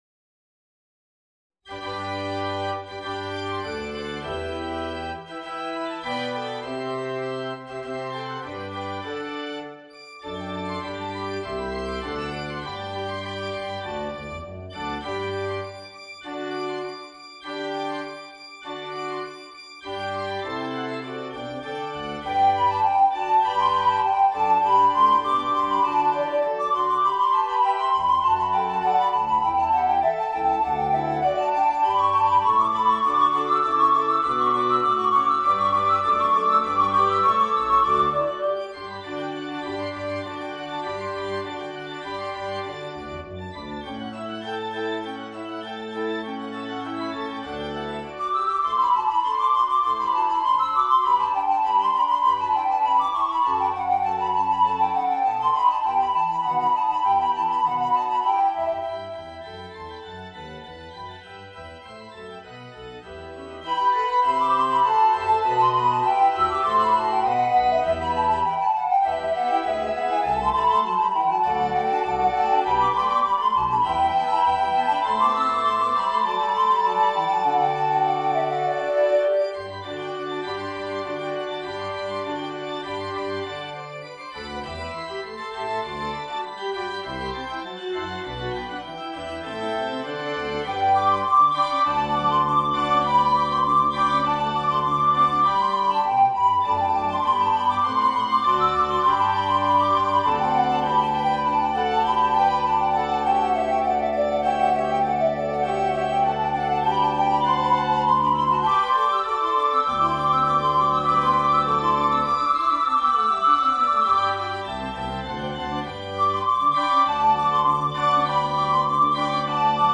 Voicing: Soprano Recorder and Organ